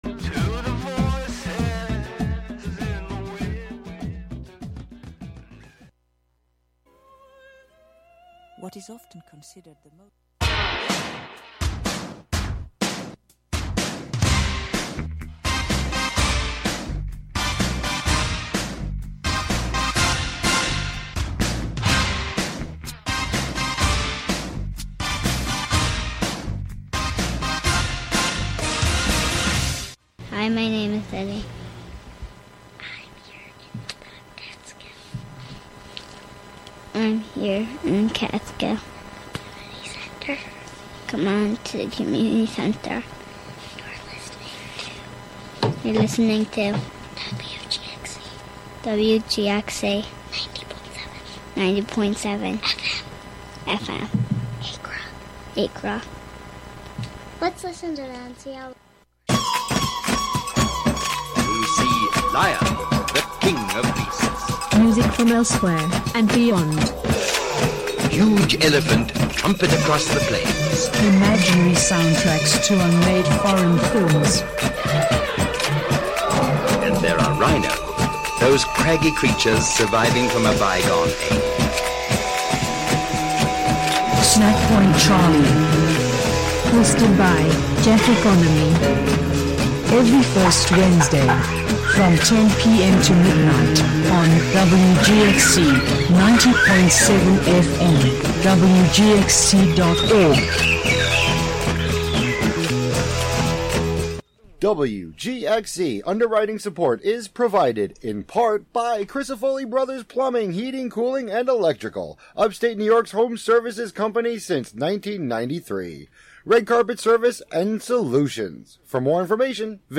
just the fictions read for the listener as best as i can read them.